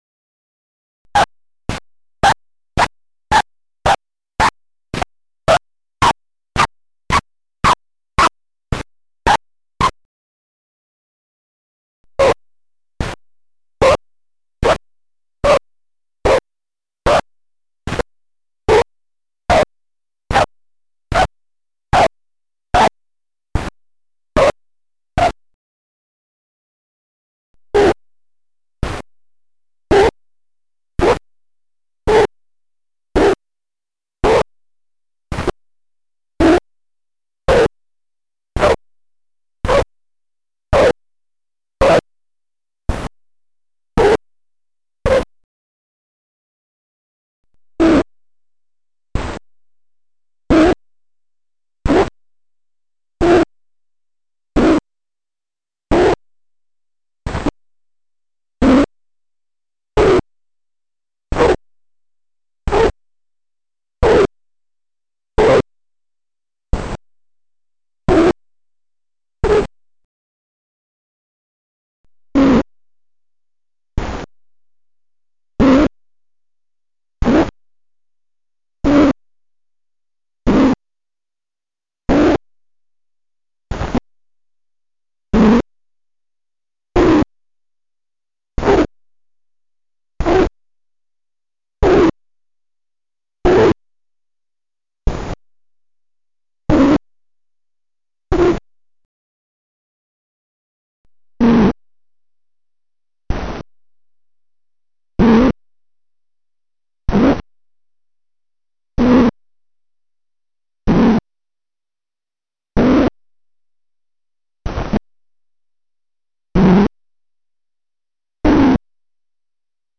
Thus, I've inserted five parts silence to every one part sound.
Here are the results, played back at ever-decreasing speeds, starting at approximately two revolutions per second: